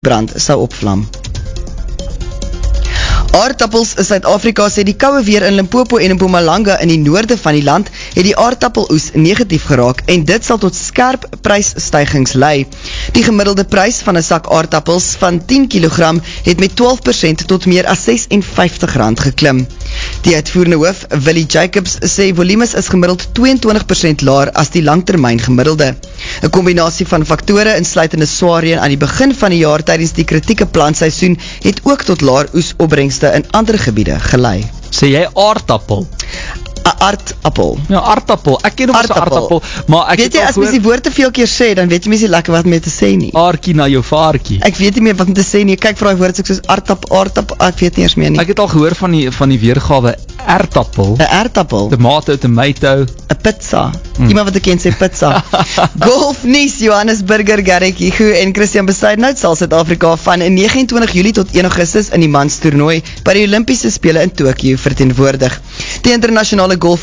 To listen to the Groot FM news clip referring to Potatoes SA, as broadcasted on 23 June 2021 at 14:00, click here.